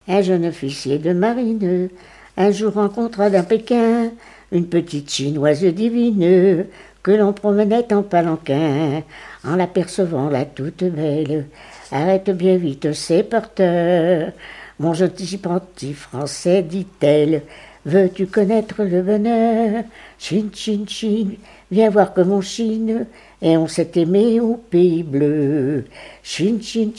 répertoire familiale de chansons
Pièce musicale inédite